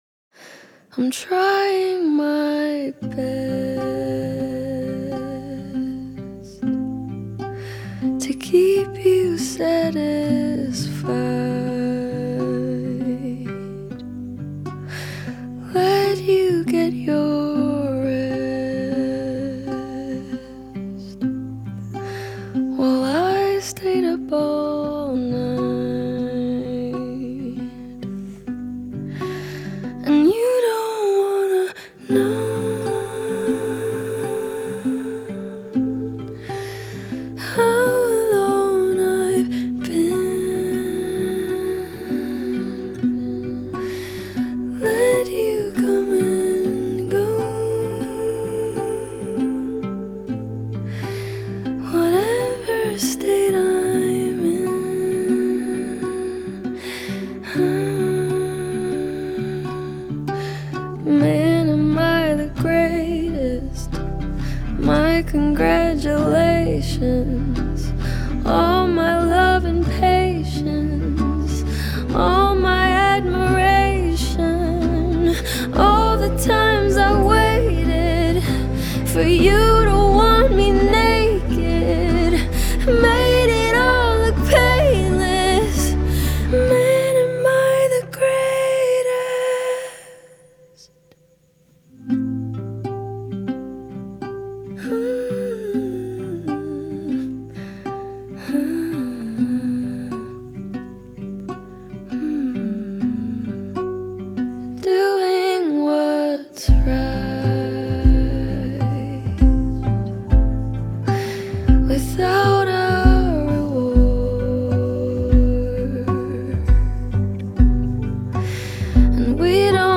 • Жанр: Indie